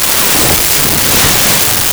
Flyby2
flyby2.wav